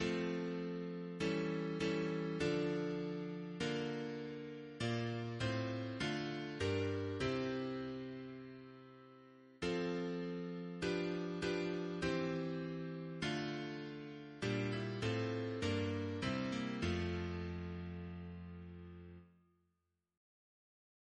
Double chant in F Composer: Thomas Attwood Walmisley (1814-1856), Professor of Music, Cambridge Reference psalters: ACB: 132; ACP: 10; CWP: 26; H1982: S249; OCB: 113A 133; PP/SNCB: 83; RSCM: 154